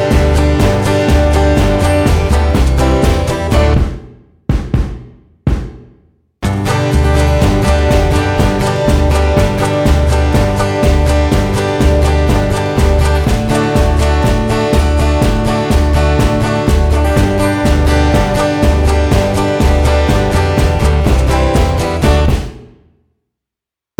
no Backing Vocals Comedy/Novelty 3:27 Buy £1.50